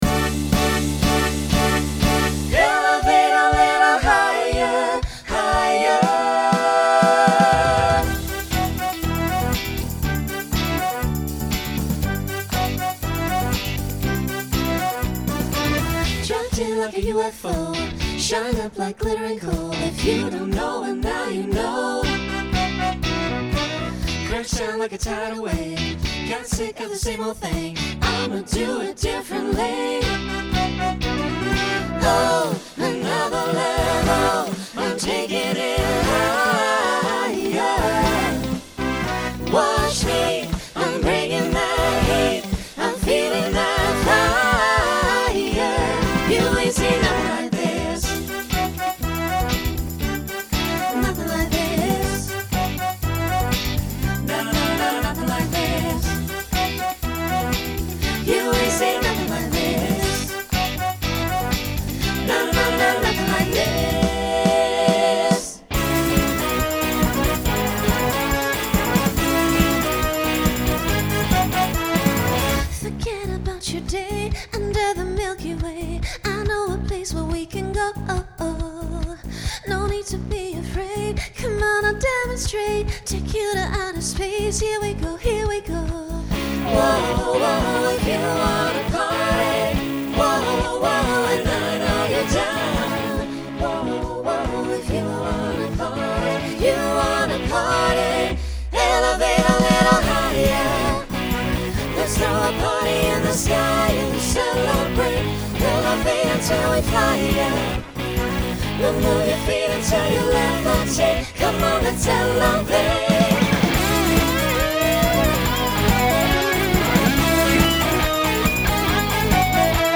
Genre Pop/Dance , Rock
Show Function Closer Voicing SATB